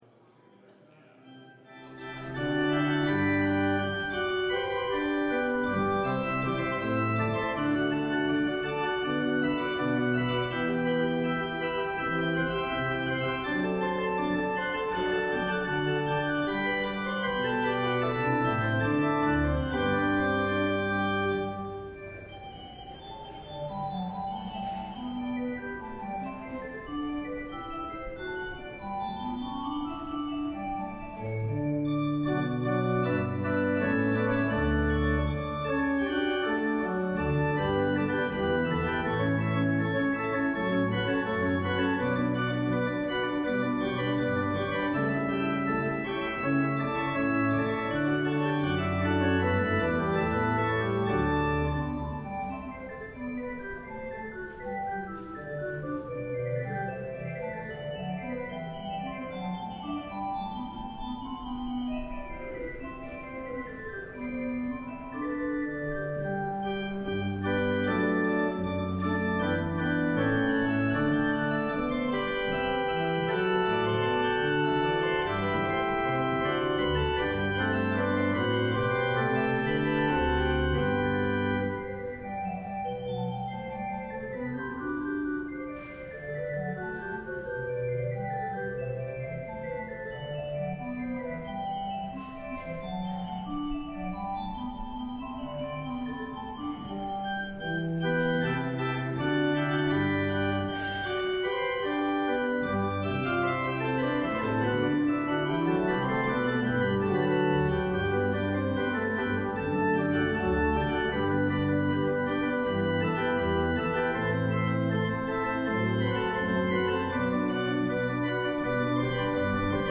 LIVE Evening Worship Service - The Grace of Kindness